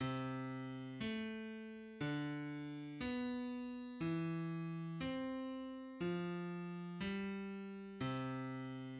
Rises by a 6th and falls by a 5th